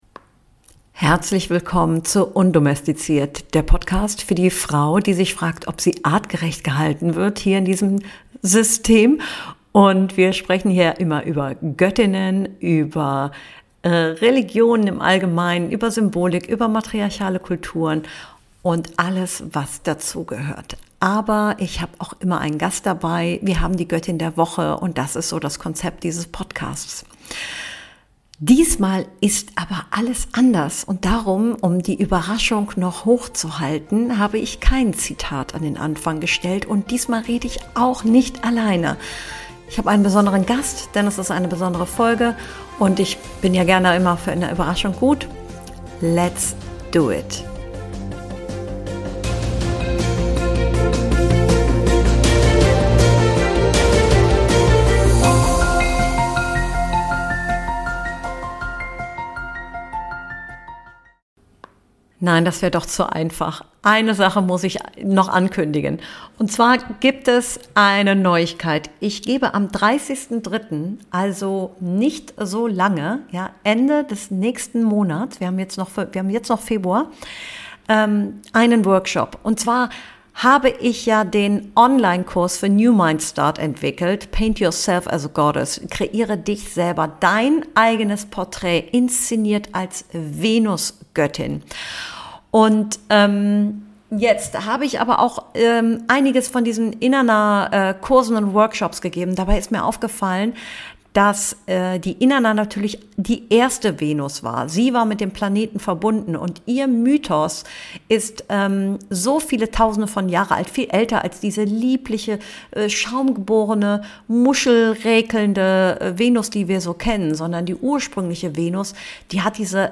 Ich diskutiere heute mit einem Überraschungsgast. In dieser Episode tauchen wir tief in die Geschichte der Menarche ein: Warum war die erste Blutung einst ein spirituelles Ereignis?